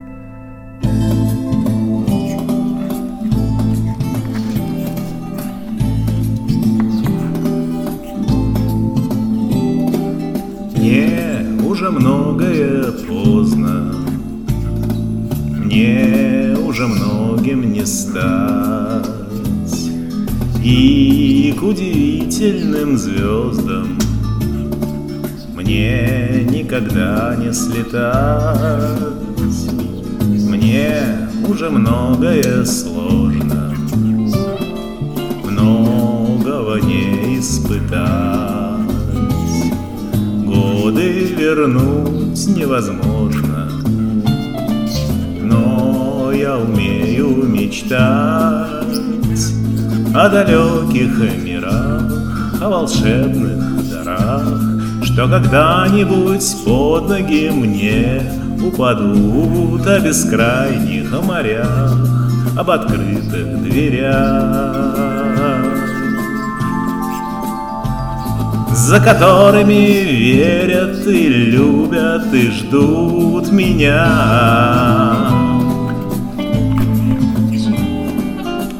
Напел на пару с попугаем. :)
Неее, он скорее контртенор :))